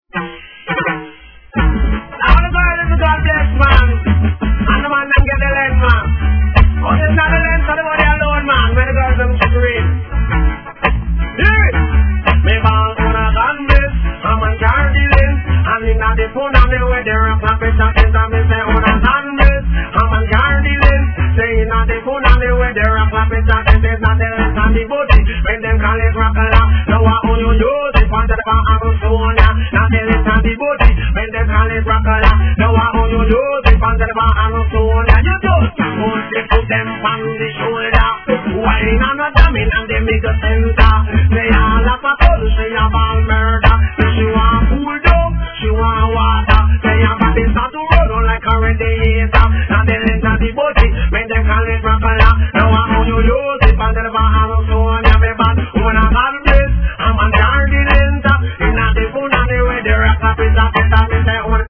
REGGAE
好DeeJay